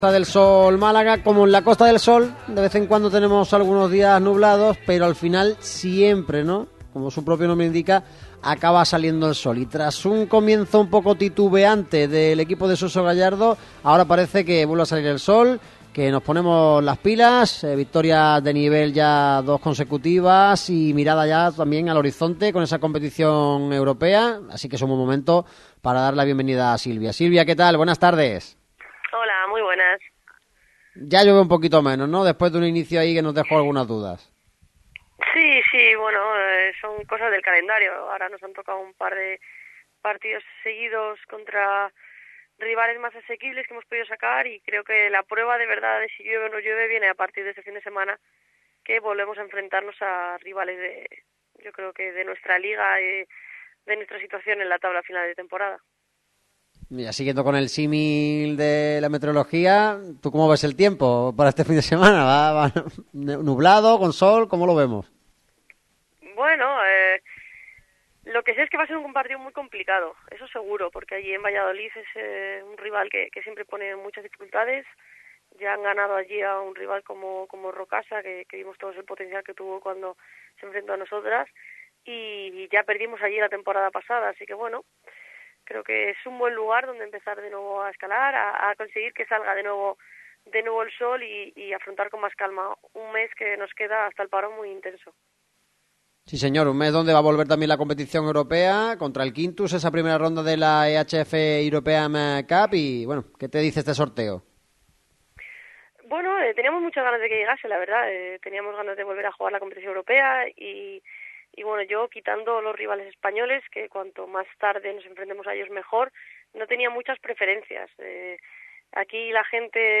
La jugadora del Costa del Sol Málaga atendió a la radio del deporte